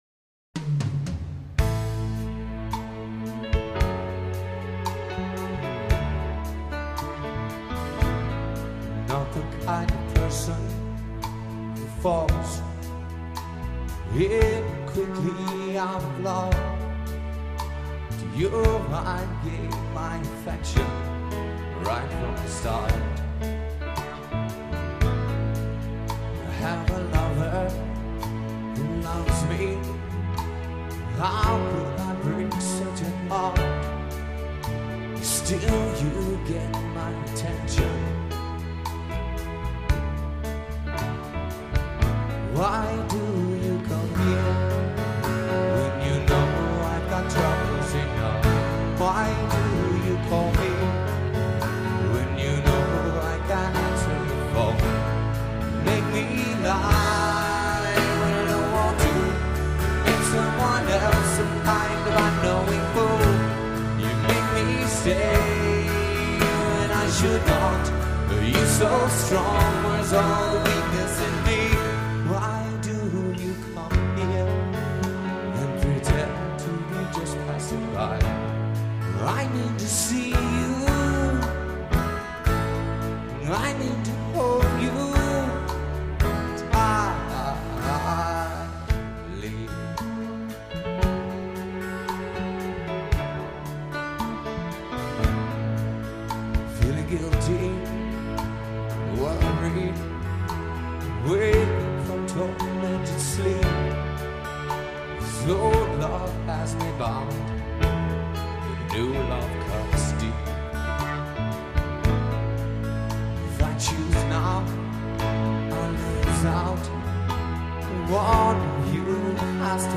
performed and recorded live